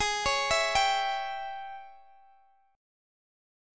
AbM7sus4#5 Chord